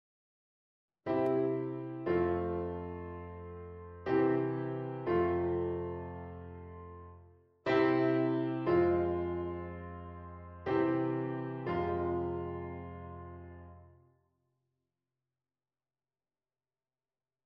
afspringende leidtoon
De leidtoon springt dus naar beneden, naar de kwint van de Ie trap, in plaats van stapsgewijs te stijgen naar de grondtoon.